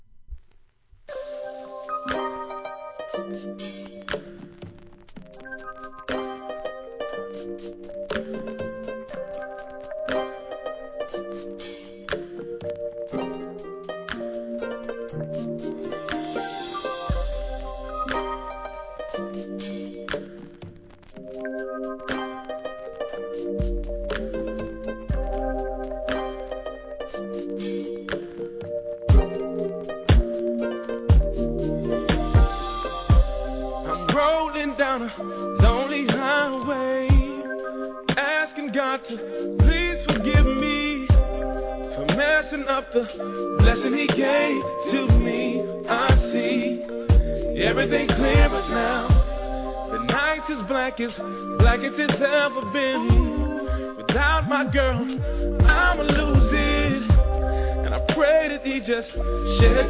1. HIP HOP/R&B
彼らの得意とする極上バラード!!